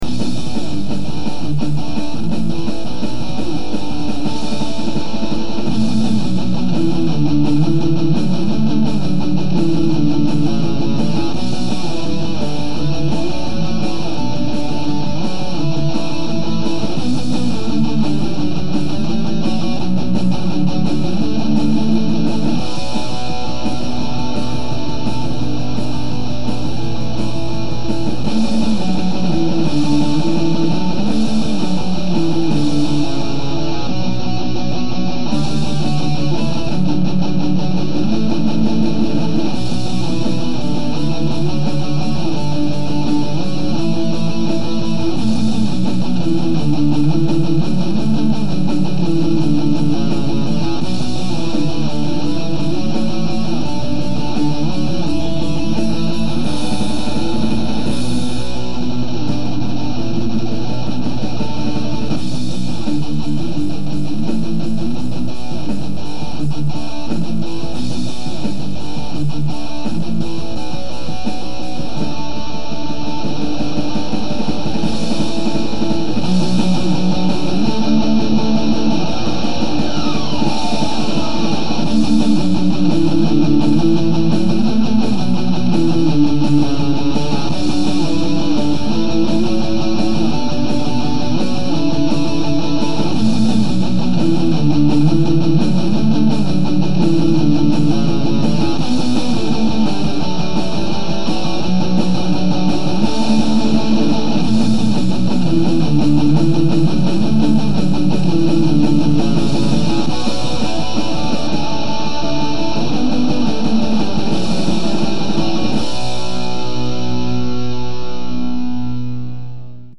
I have a preview of my work here i recorded using audacity for those who cant or dont want to load the flash file... however i reccomend listening to the flash file if possible for higher quality... my mic is shit.. so it doesnt record too well, and i forgot where my line-in wires are.